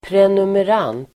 Ladda ner uttalet
Uttal: [prenumer'an:t]